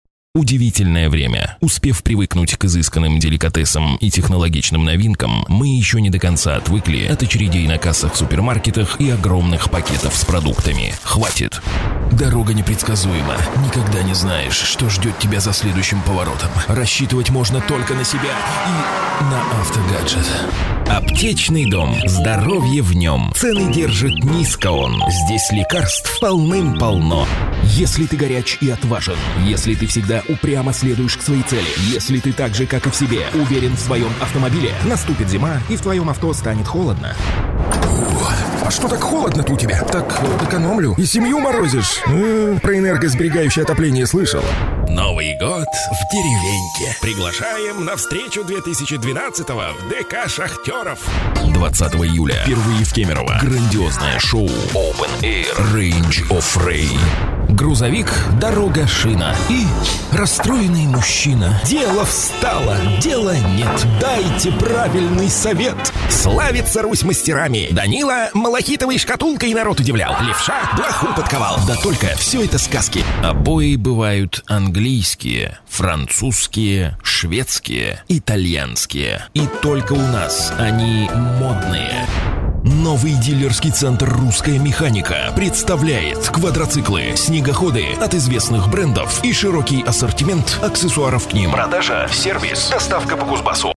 Профессиональный диктор.
Тракт: RODE NT1-A - Focusrite Platinum Trak Master - Alpha Lexicon.Дикторская кабина